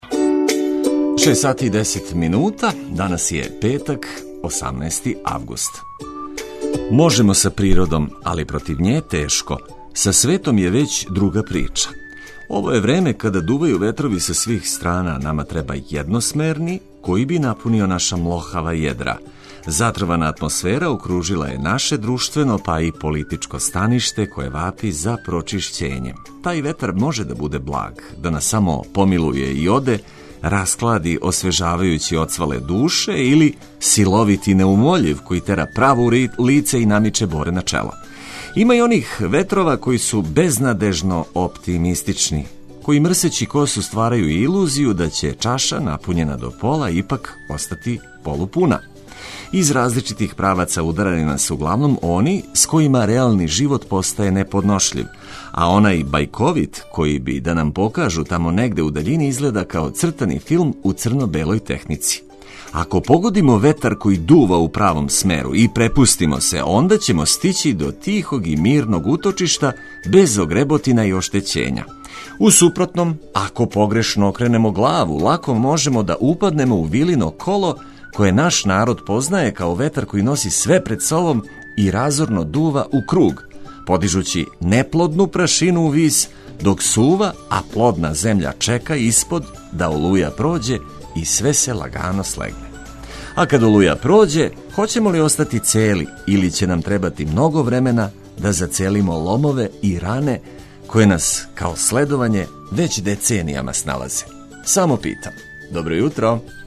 За лакше буђење уз корисне вести и ведру музику за устајање задужени смо ми.